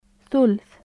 舌先に上の歯を軽く当てた隙間から，勢いよく呼気を通して発音される/θ/の音で，英語ではthで表されています。この/θ/を発音する際には，声帯は振動させません。